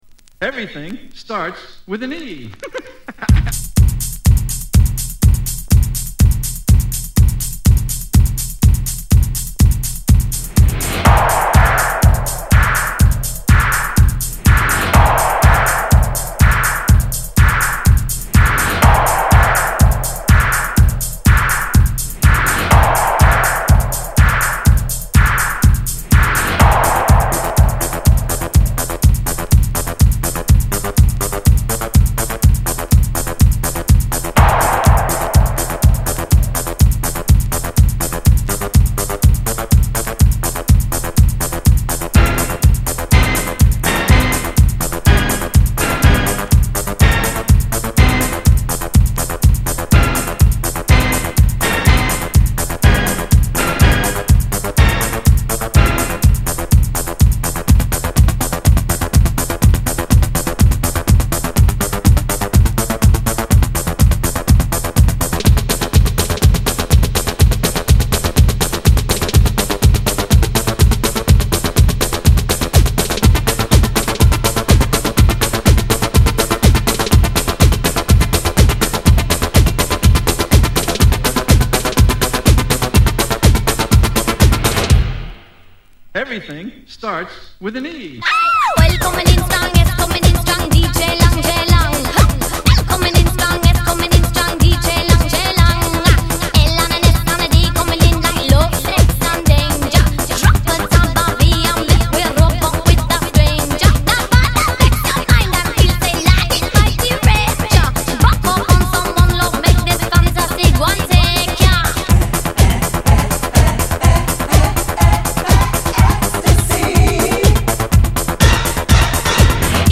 a four-to-the-floor, hands-in-the-air dancefloor banger
box-fresh ragga toasting
a steady 120 beats per minute groove